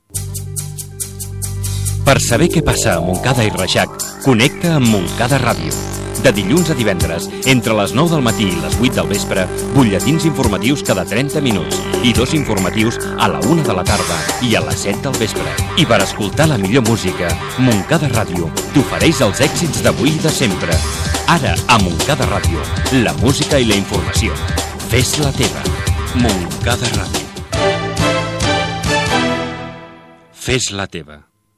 Identificació de l'emissora i horari dels programes informatius